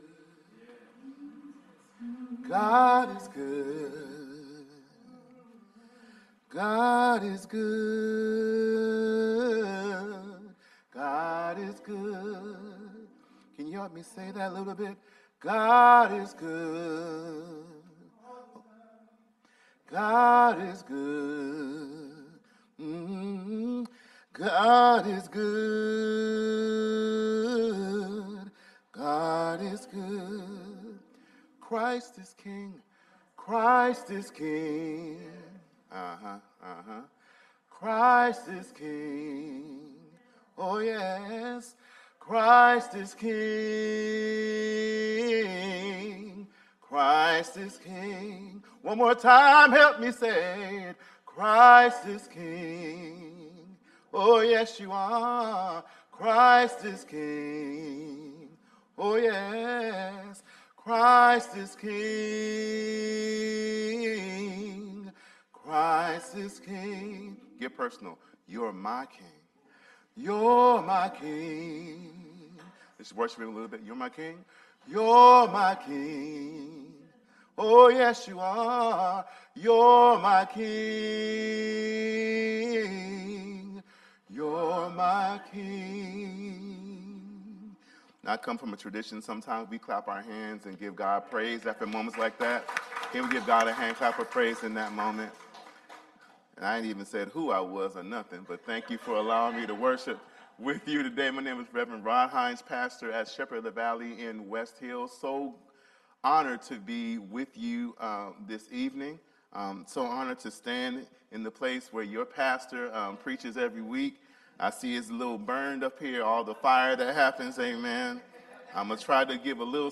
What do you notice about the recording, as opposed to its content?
NOTE: We had some video issues so some of the video feed is missing, but the audio is still there.